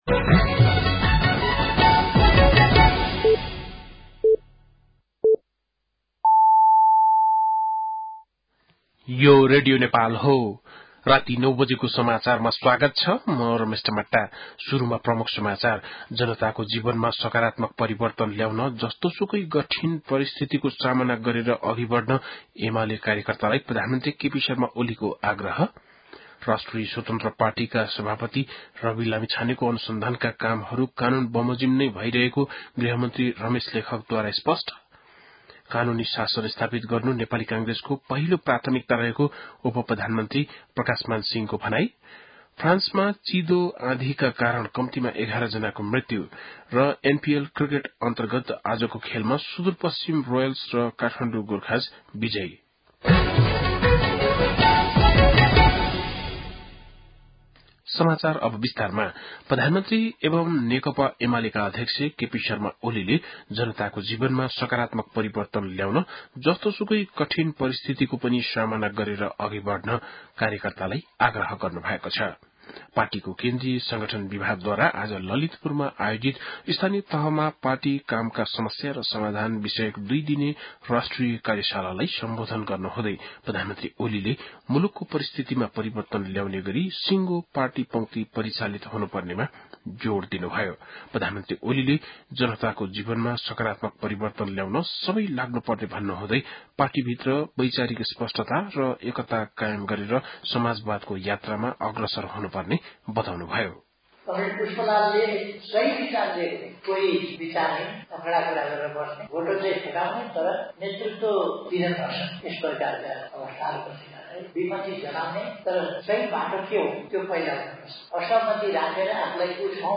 बेलुकी ९ बजेको नेपाली समाचार : १ पुष , २०८१
9-PM-Nepali-News-8-30.mp3